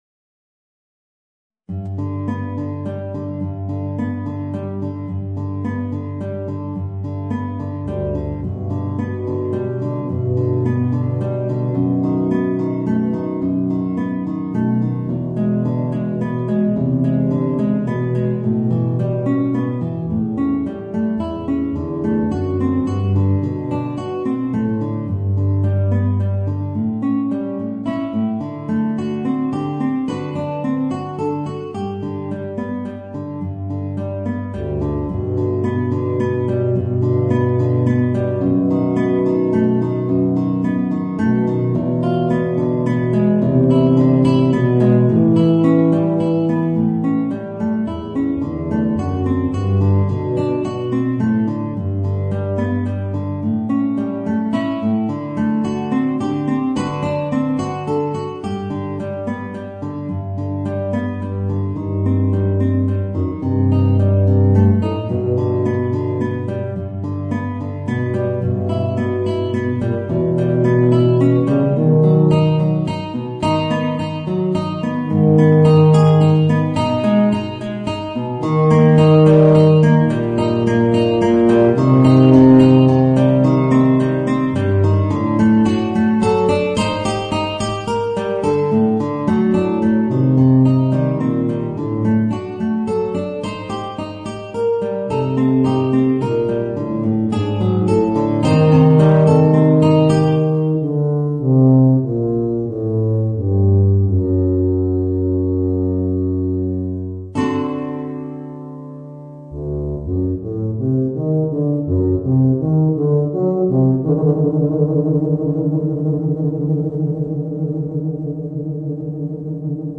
Voicing: Tuba and Guitar